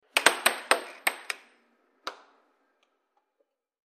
Air Hockey; Puck Hits And Bounce On Table.